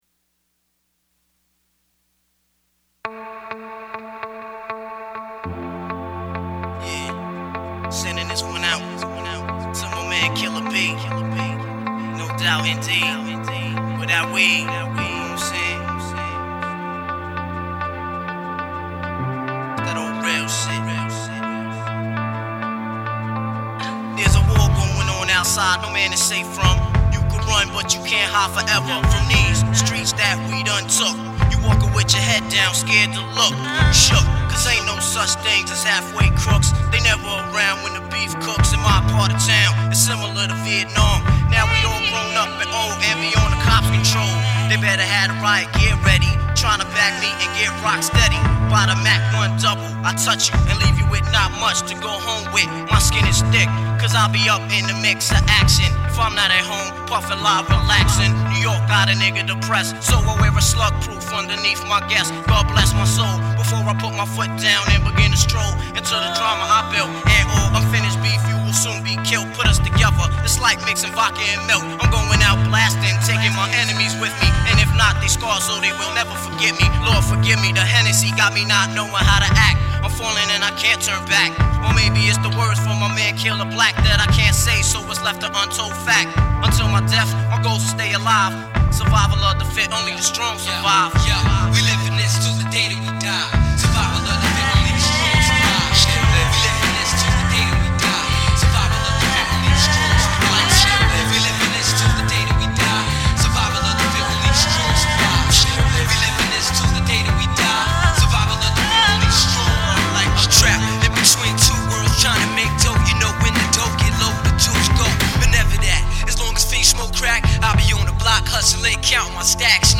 Mashups Mash-up Remix